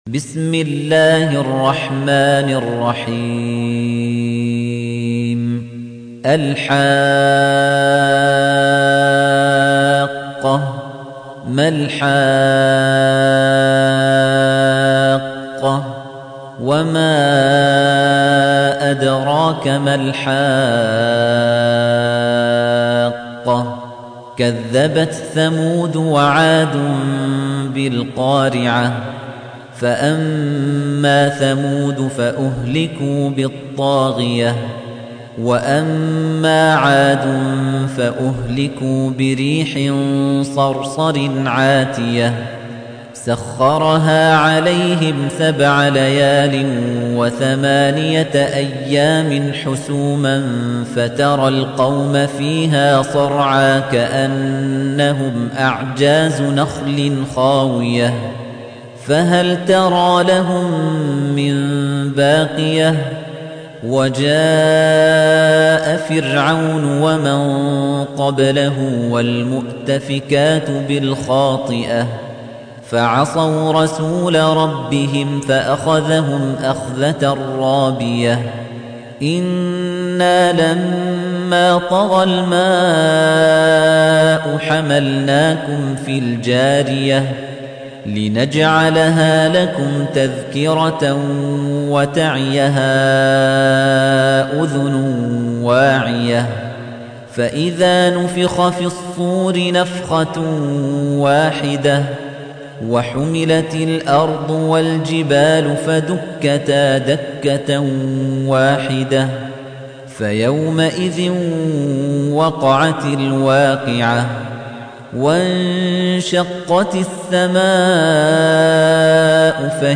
تحميل : 69. سورة الحاقة / القارئ خليفة الطنيجي / القرآن الكريم / موقع يا حسين